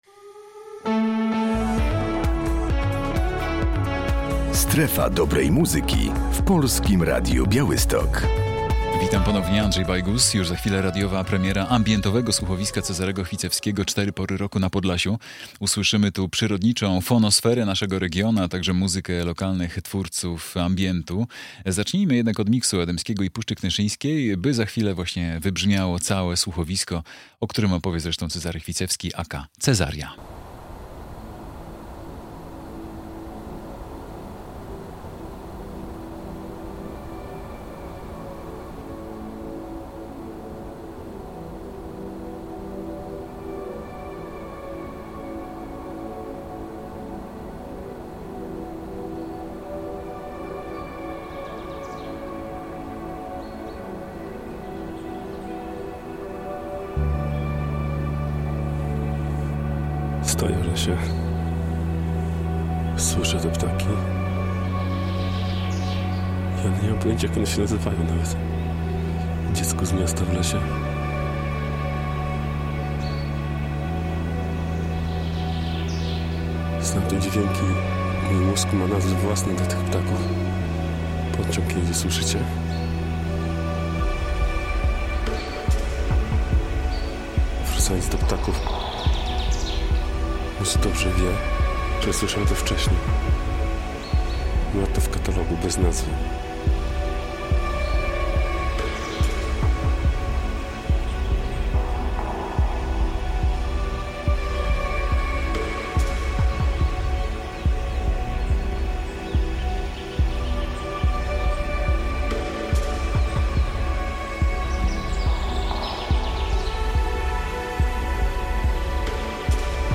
Radio Białystok | Muzyczne | !pod powierzchnią! | Ambientowe słuchowisko
Przy dźwiękach słuchowiska